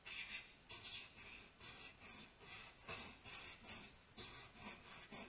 ST锯塑料表面
描述：锯开一个塑料表面
标签： 塑料 表面
声道立体声